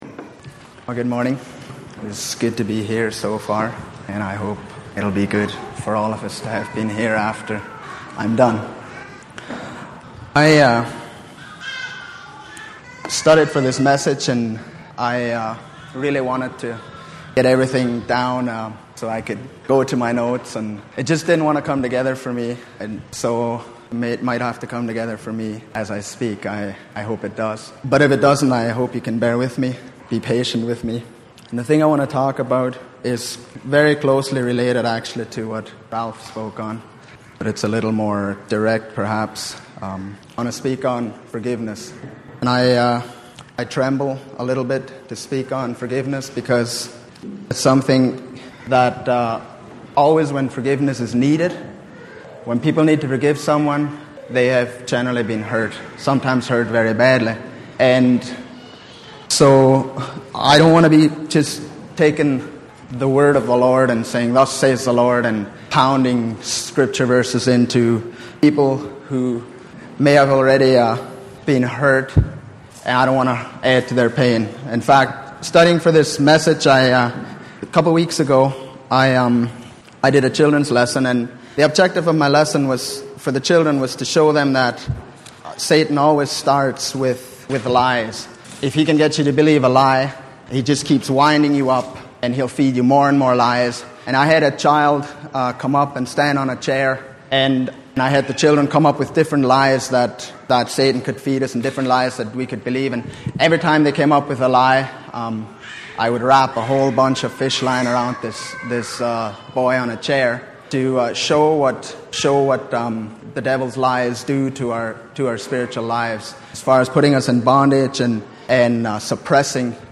Series: Sunday Morning Sermon Passage: Matthew 18:18-35 Service Type: Sunday Morning